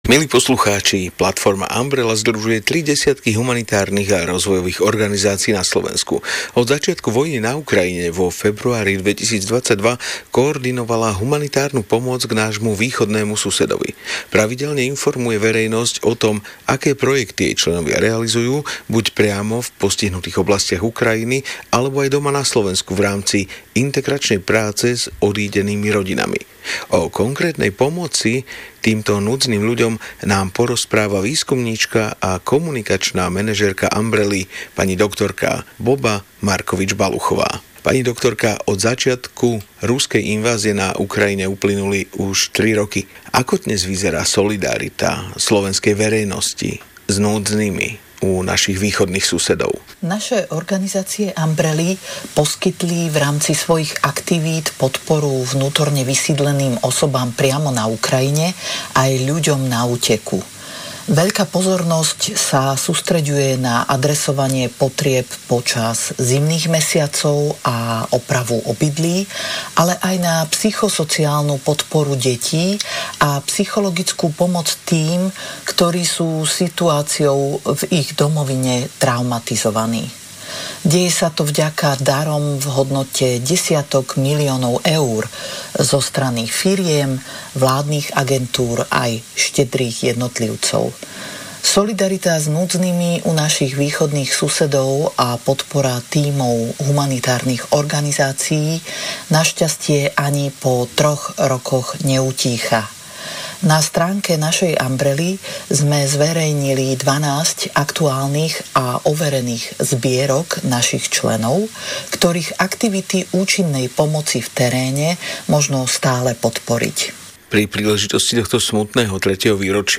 O 3 rokoch pomoci Slovenska Ukrajine – rozhovor (